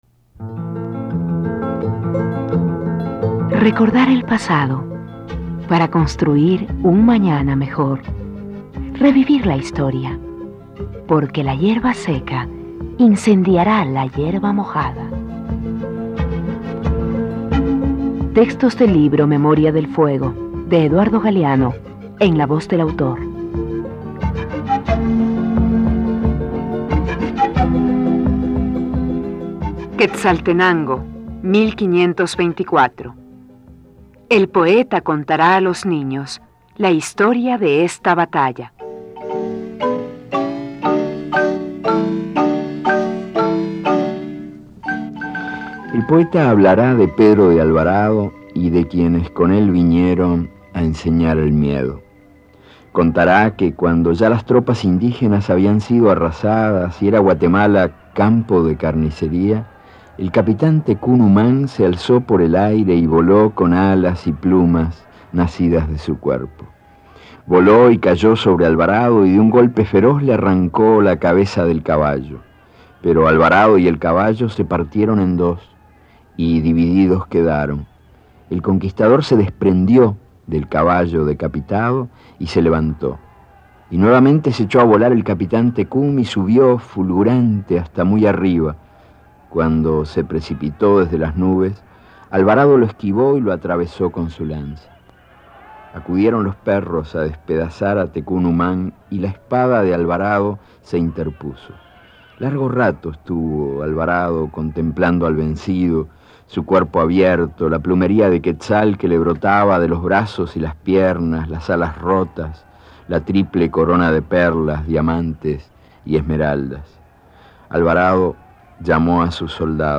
Relato en prosa perteneciente a Memoria del fuego I-Los nacimientos, primer libro de la trilogía del escritor Eduardo Galeano, que narra la historia de América Latina hasta el siglo XX.
Este archivo de sonido ofrece la lectura del texto en la voz de su autor.